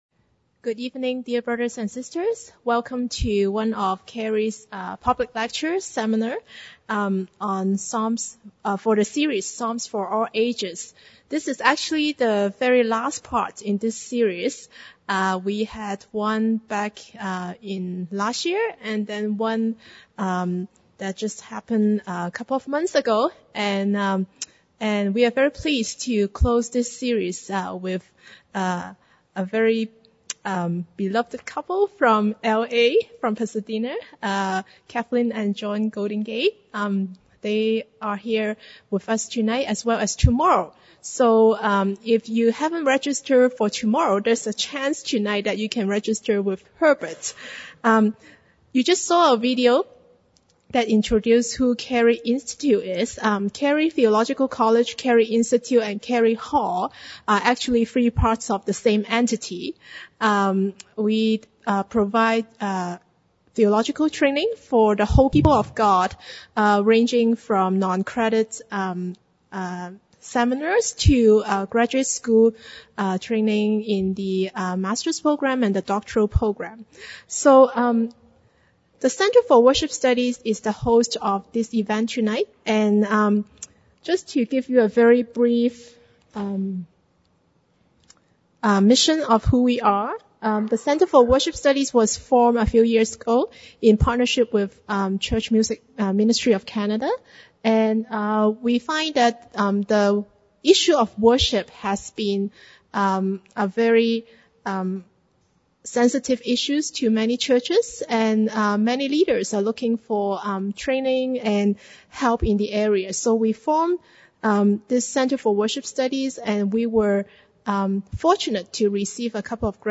free public lecture